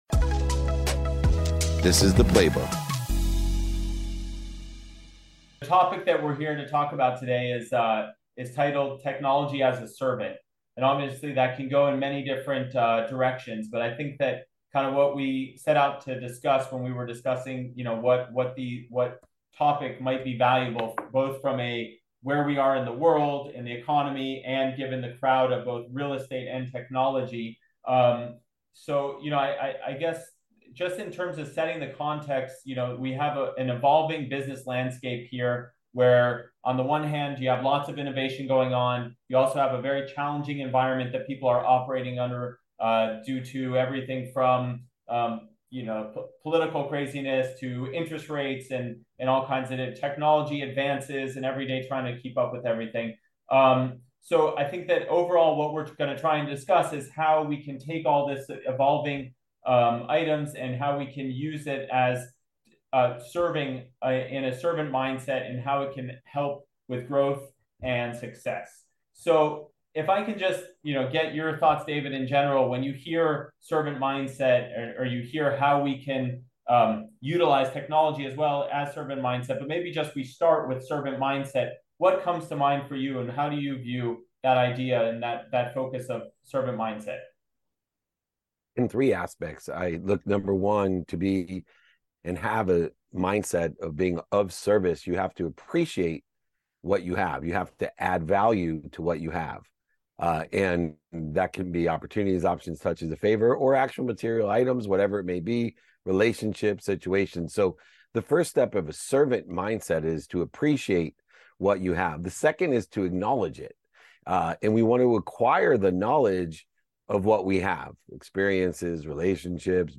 Today’s episode is from a conversation